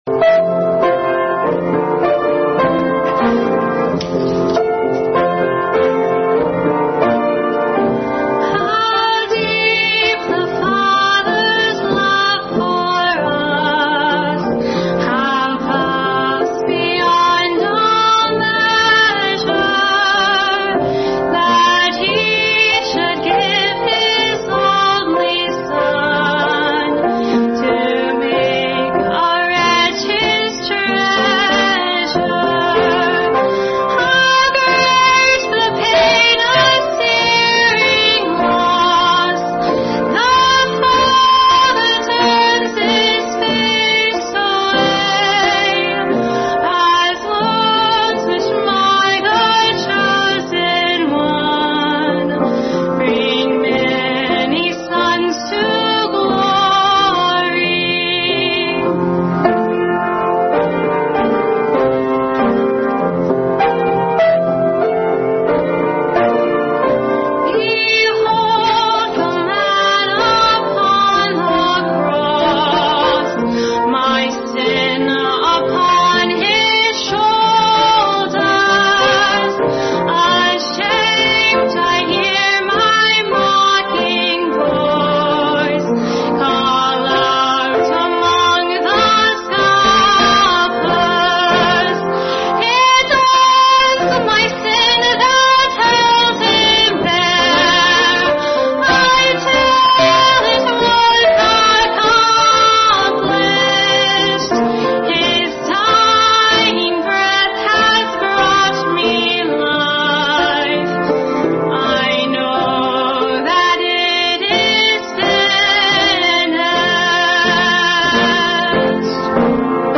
| How Deep the Father’s Love. Soloist
Piano